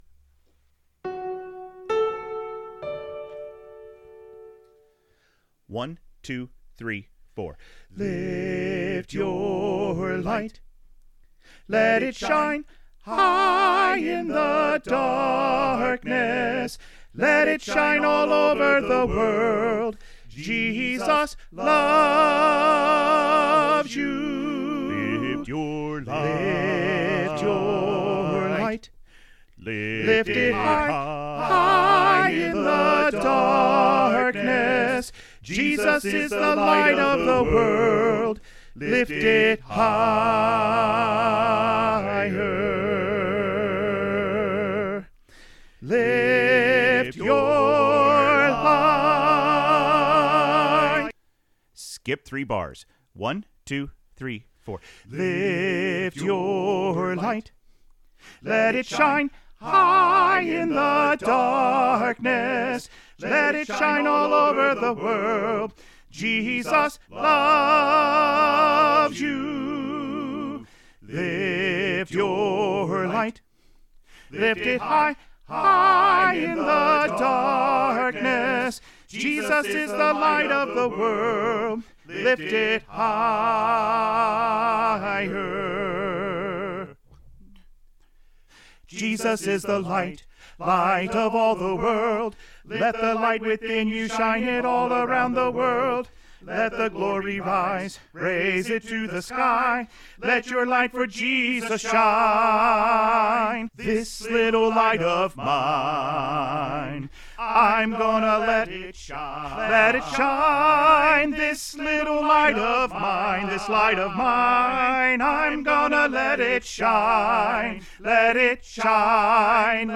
Choir Music Learning Recordings
Lift Your Light - Tenor Emphasized All 4 Parts with The Tenor Part Emphasized